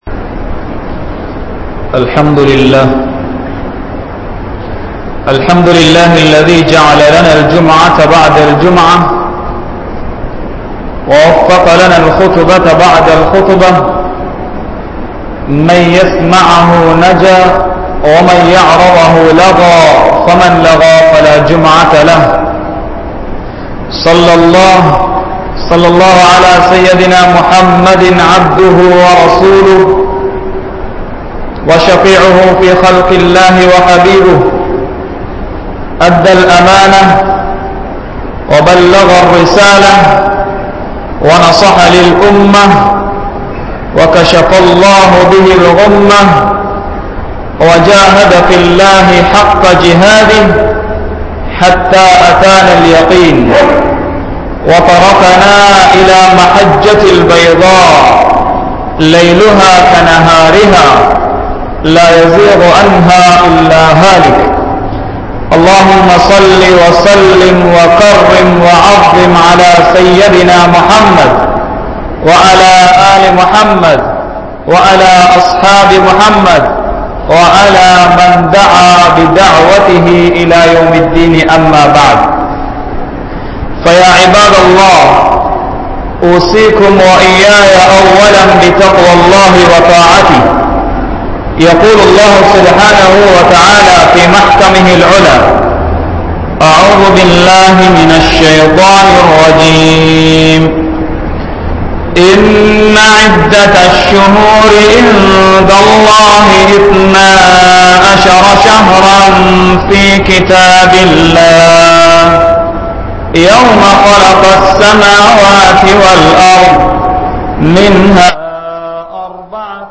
Maraniththa Ullangal (மரணித்த உள்ளங்கள்) | Audio Bayans | All Ceylon Muslim Youth Community | Addalaichenai
Theliyagonna Jumua Masjidh